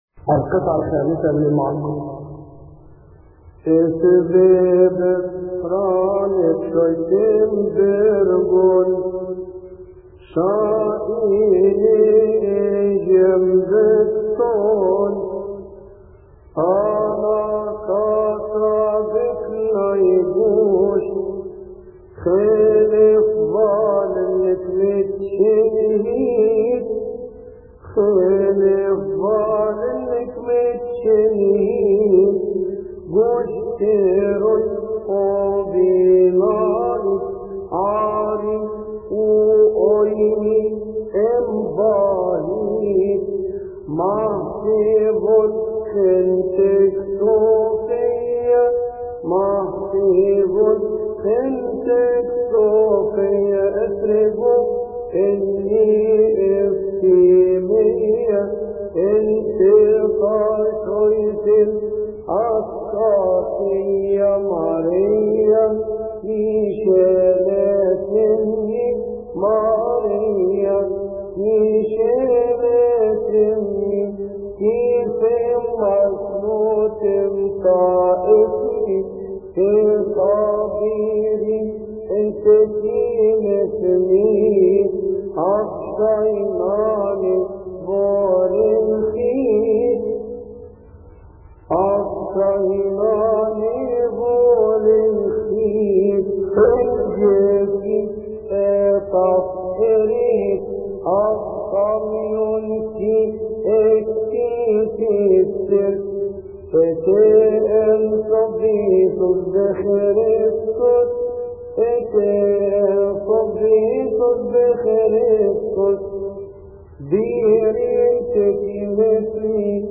مكتبة الألحان
يصلي في تسبحة عشية أحاد شهر كيهك